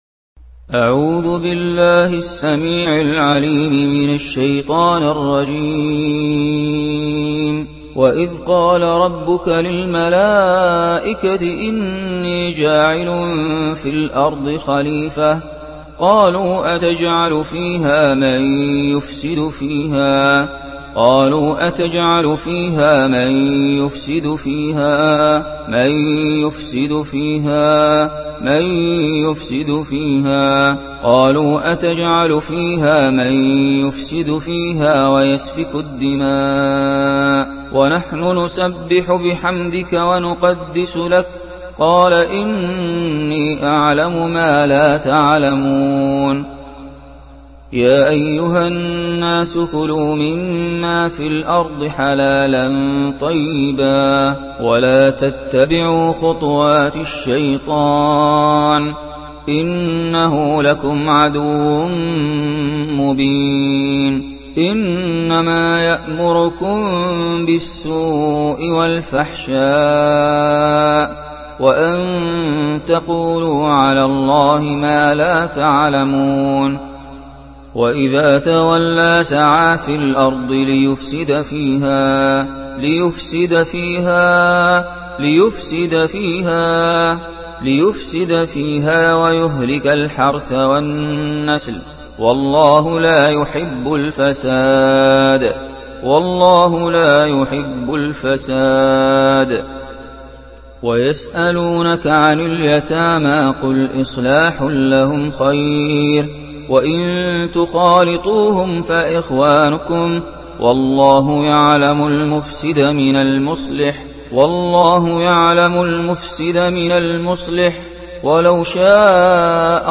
রুকইয়াহ যিনা — Ruqyah zina
রুকইয়াহ-যিনা-—-Ruqyah-zina.mp3